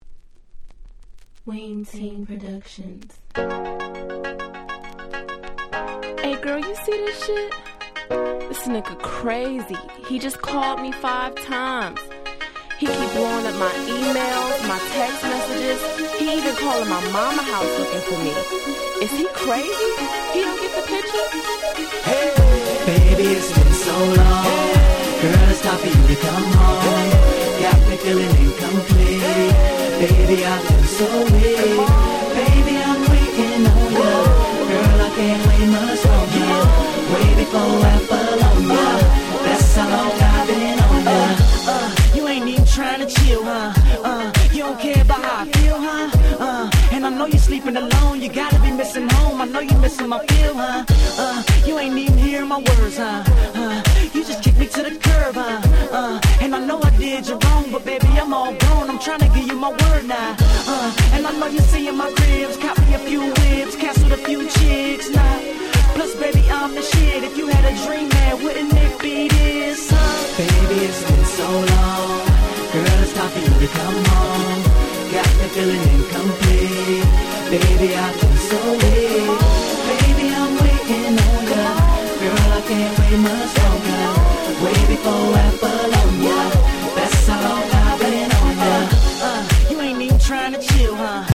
White Press Only Remixes !!
Hip Hop R&B 00's